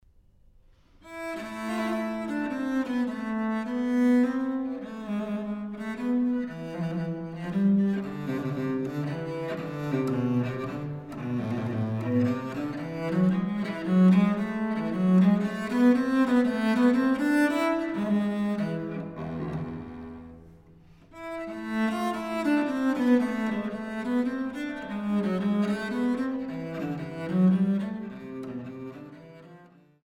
Courante (Ebenthal, Goess A)